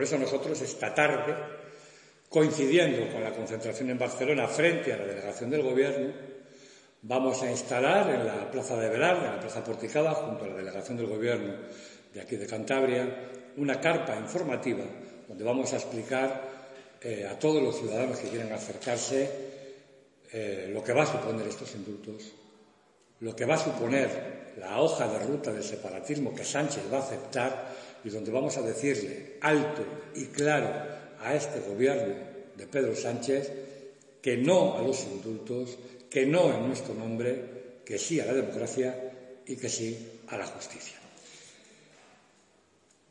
Es lo que ha sostenido hoy en rueda de prensa el coordinador de Ciudadanos (Cs), Félix Álvarez, quien esta tarde estará presente, desde las 19.00 a las 21.00 horas, en la carpa informativa que la formación liberal instalará en la santanderina Plaza Porticada, coincidiendo con la concentración que va a tener lugar en Barcelona en contra de los indultos a los condenados por sedición y malversación de fondos públicos.